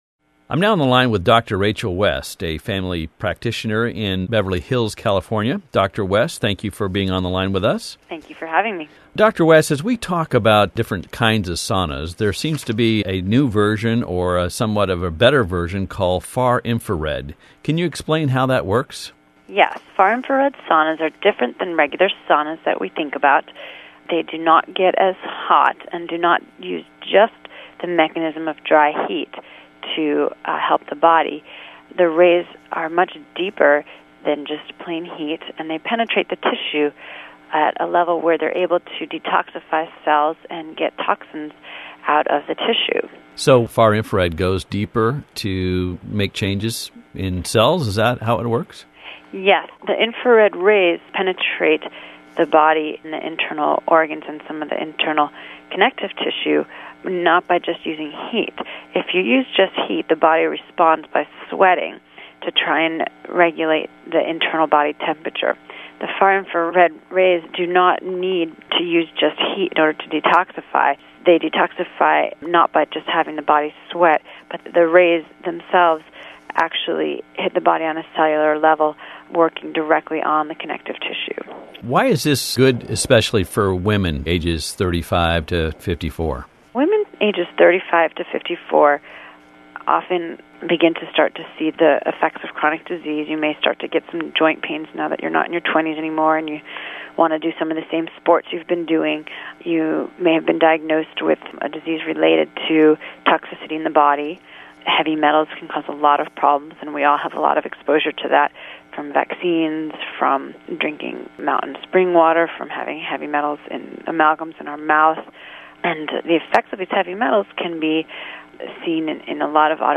Testimonial